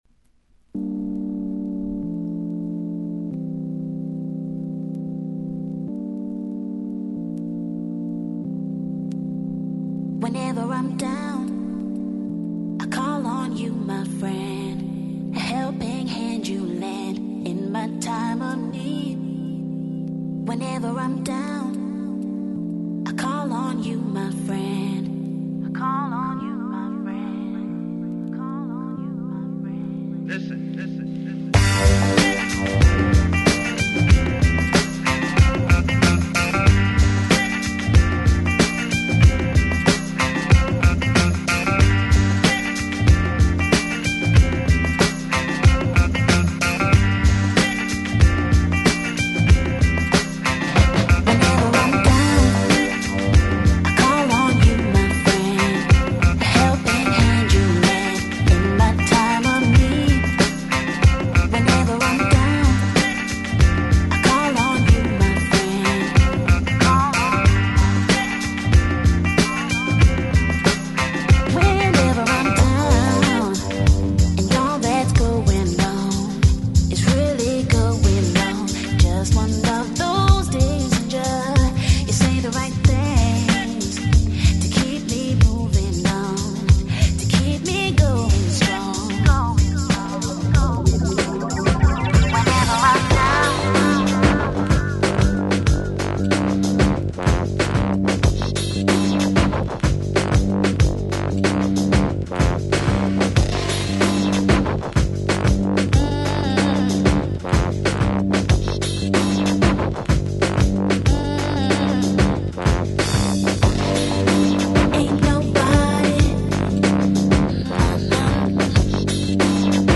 A little Techno, don't you know!